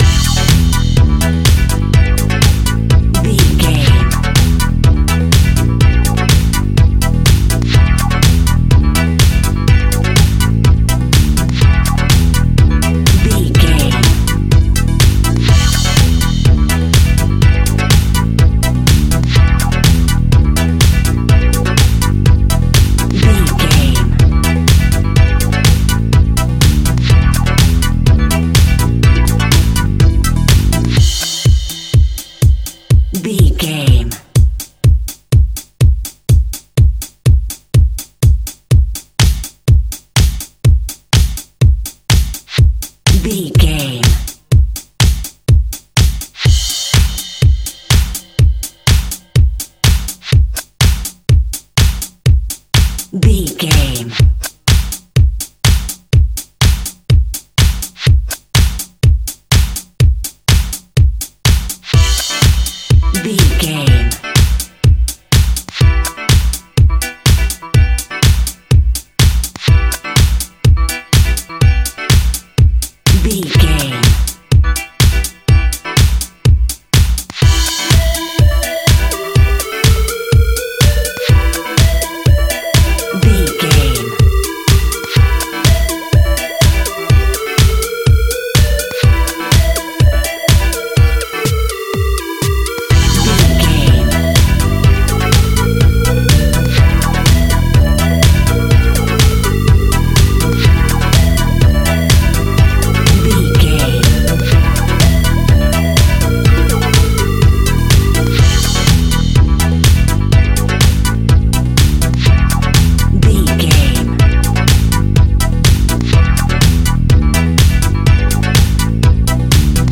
Funky House Music Cue.
Aeolian/Minor
groovy
uplifting
driving
energetic
drum machine
synthesiser
electro house
synth pop
joyful